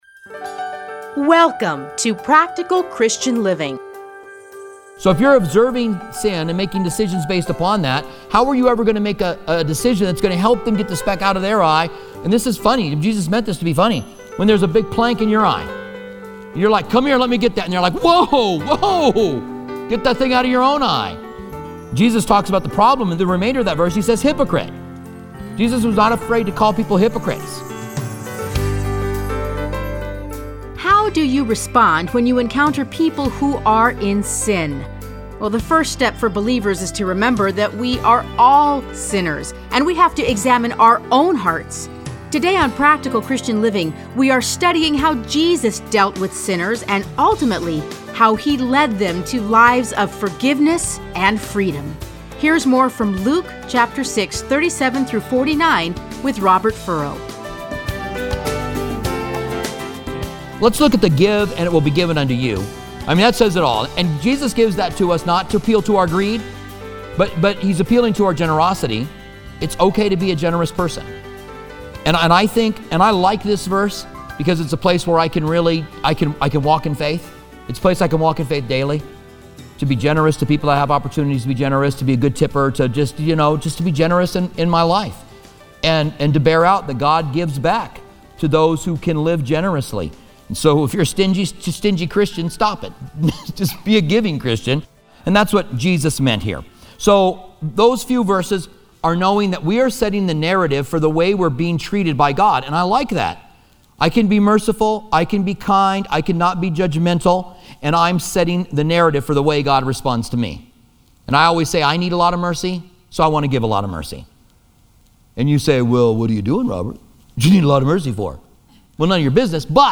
Listen to a teaching from Luke 6:37-49.